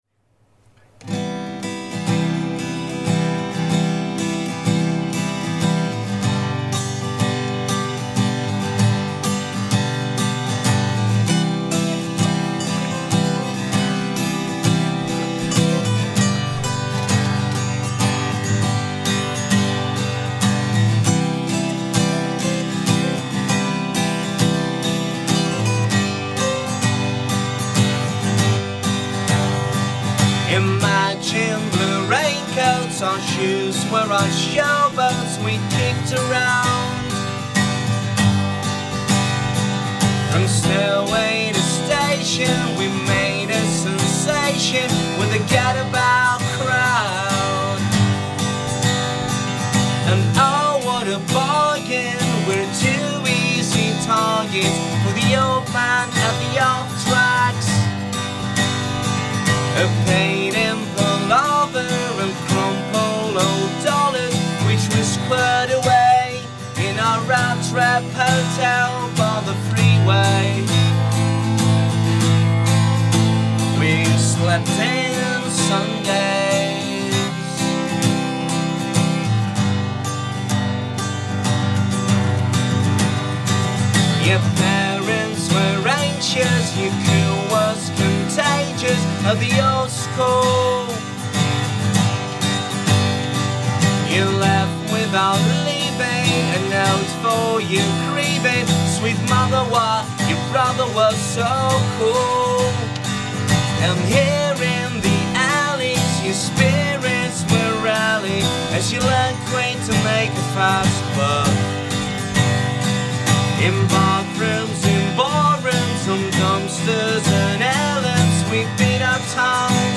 live unplugged